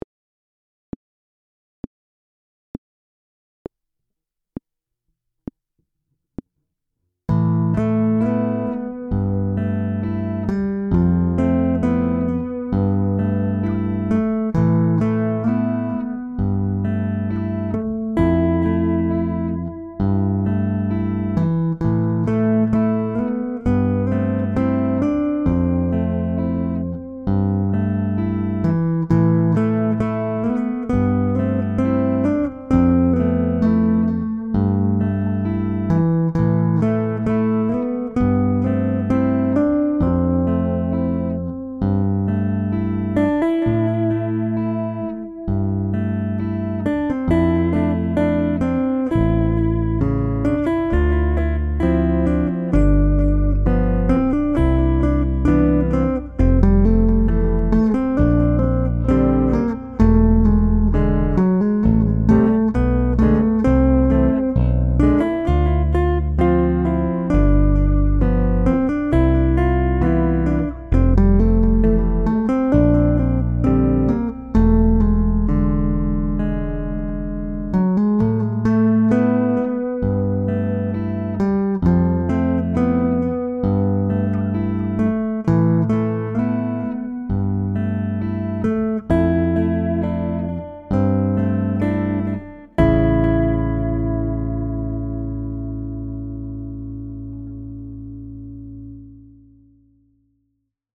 • Instrumental
It is fitting that the piece is in this key.